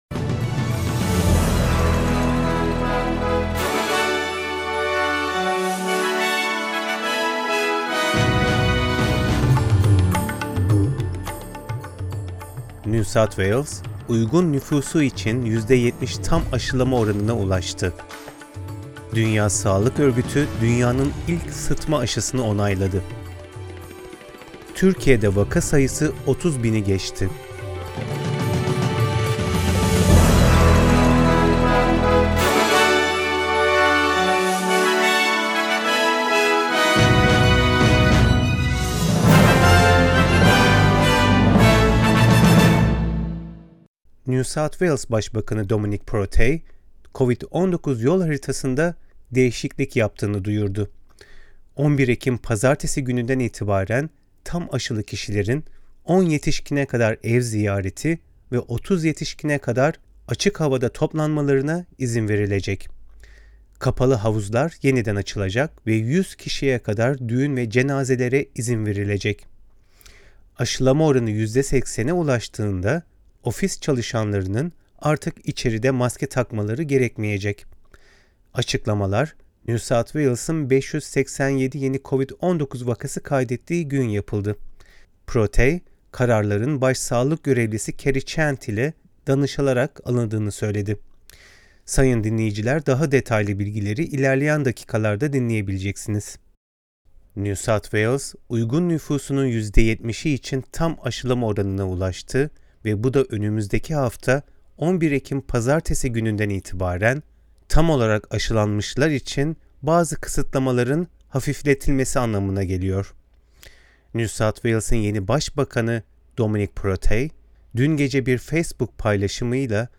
SBS Türkçe Haberler 7 Ekim